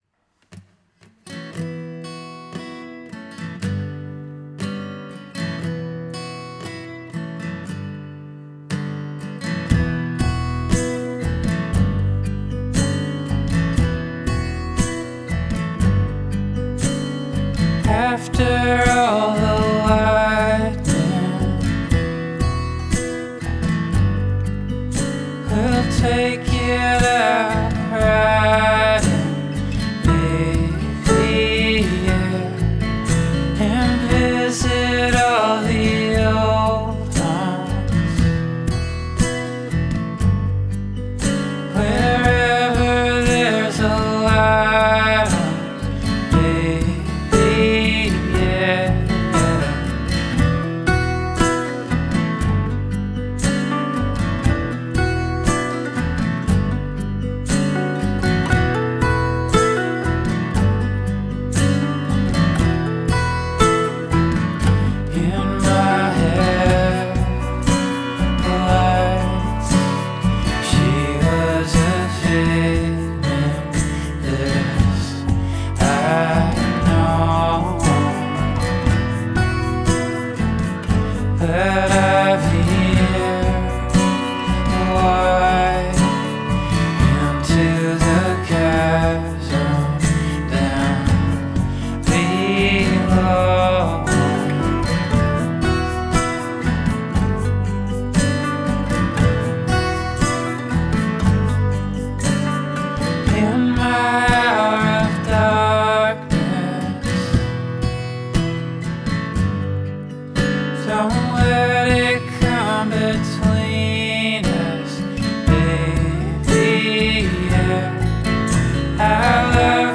contemporary folk musician